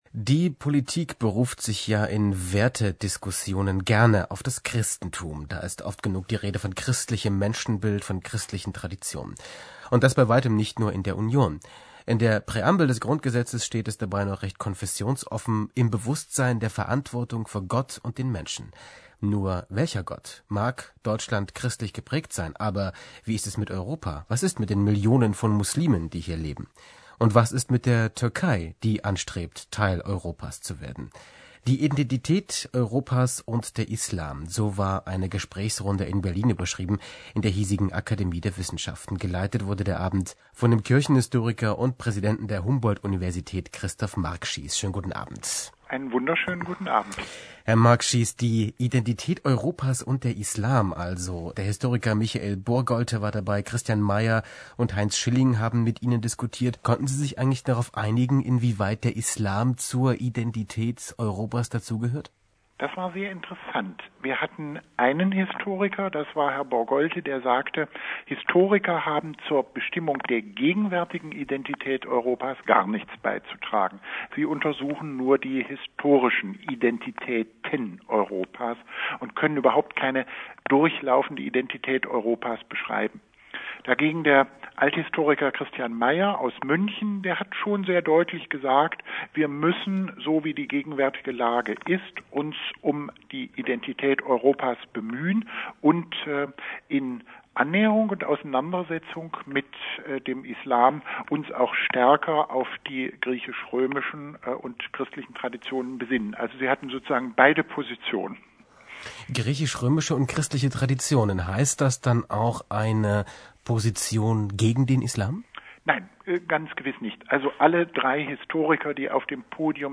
Identita_Europa_Islam-Interview_Prof._Markschies.mp3